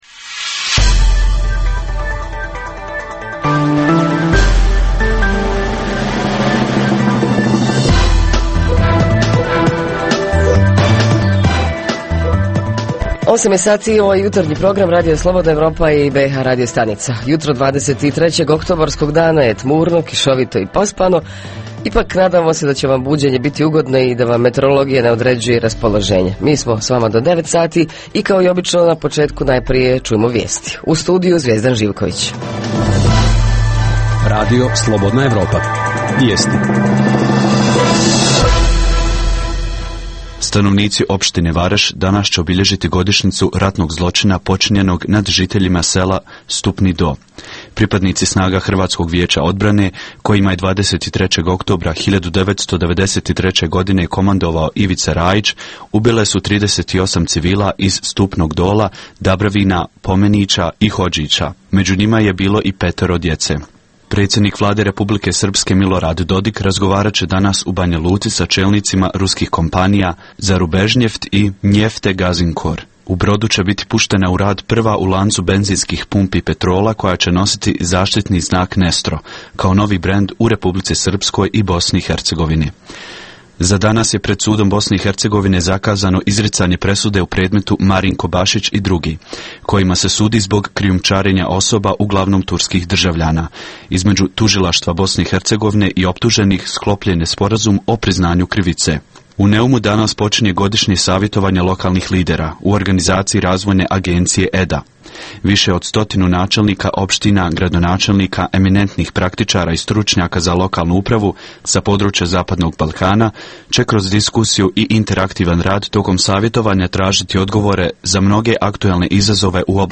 Kako ocjenjujemo sociološko – psihološko stanje u našim gradovima, kakva je kultura ponašanja, moral odnosno etika u društvu Redovna rubrika Radija 27 petkom je “Za zdrav život". Redovni sadržaji jutarnjeg programa za BiH su i vijesti i muzika.